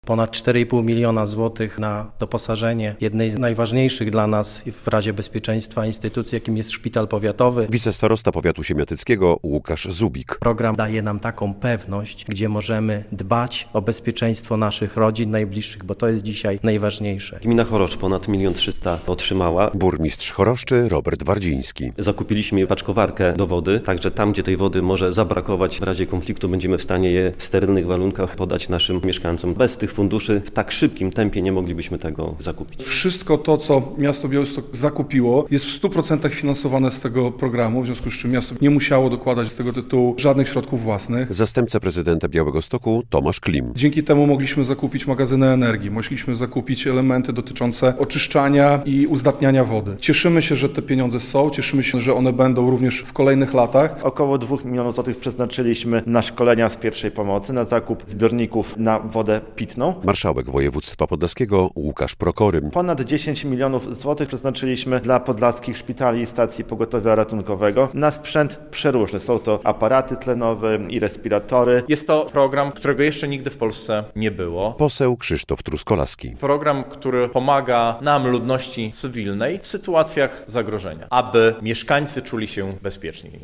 Podlaskie samorządy wykorzystały już ponad 90 proc. pieniędzy przeznaczonych na wzmacnianie obrony cywilnej w regionie w tym roku. Poinformował o tym na wspólnej konferencji prasowej z samorządowcami poseł Koalicji Obywatelskiej Krzysztof Truskolaski.